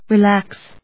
音節re・lax 発音記号・読み方
/rɪlˈæks(米国英語), ri:ˈlæks(英国英語)/